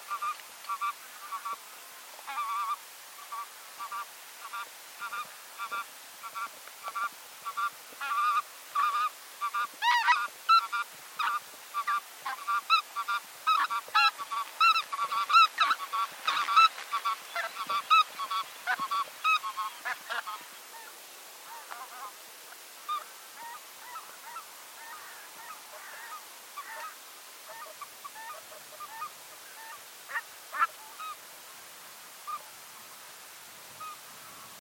Oie rieuse - Mes zoazos
oie-rieuse.mp3